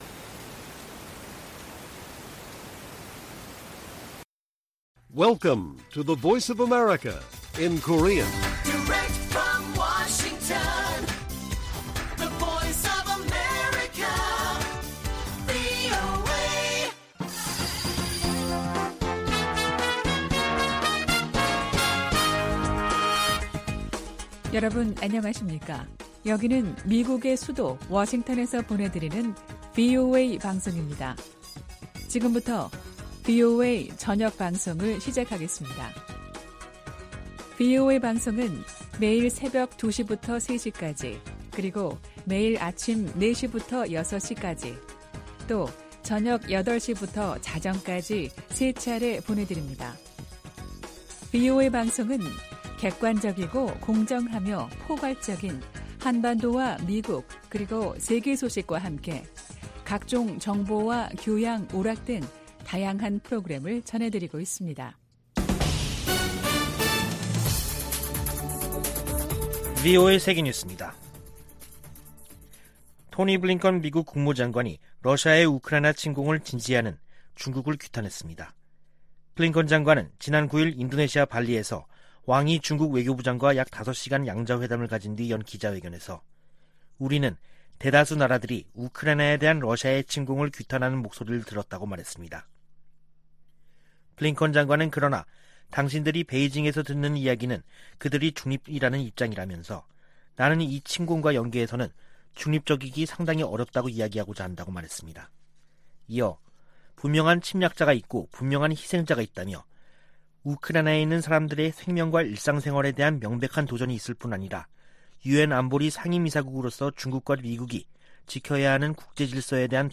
VOA 한국어 간판 뉴스 프로그램 '뉴스 투데이', 2022년 7월 11일 1부 방송입니다. 북한이 한 달 만에 또 다시 서해로 방사포 2발을 발사했습니다. 미-한-일은 주요 20개국(G20) 외교장관 회의를 계기로 3자 회담을 갖고 안보협력 확대 방안을 협의했습니다. 미국과 한국이 다음달 22일부터 9월 1일까지 미-한 연합지휘소훈련(CCPT)을 진행하기로 했습니다.